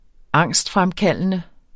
Udtale [ -ˌfʁamˌkalˀənə ]